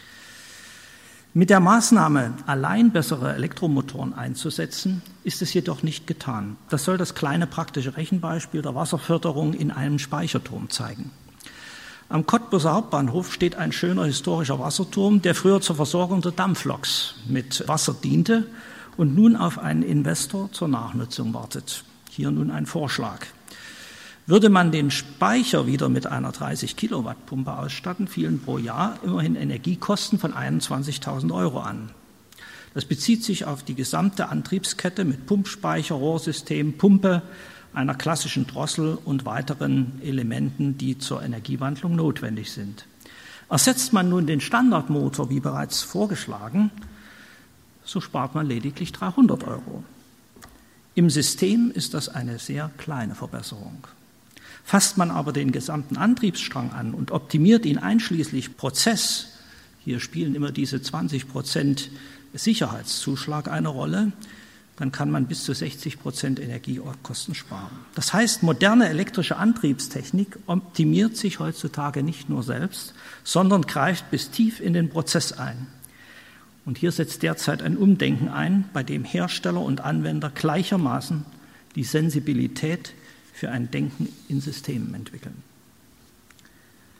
Zum unten wiedergegebenen Text gehört die Festansprache